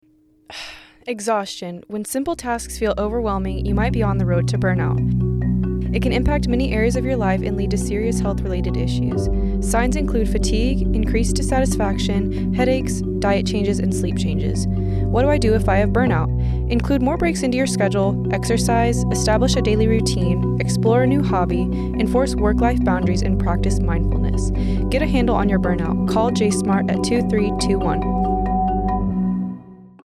A radio spot explaining the signs of burnout and directing sailor is need to reach out to the JSMART clinic.